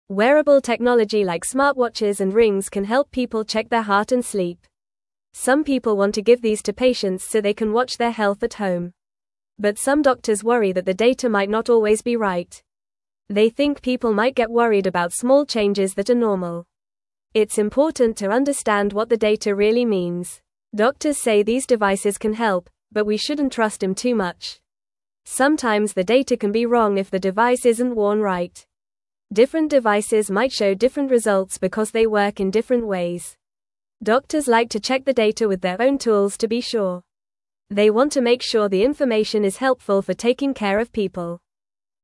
Fast
English-Newsroom-Beginner-FAST-Reading-Smart-Devices-Help-Check-Health-at-Home.mp3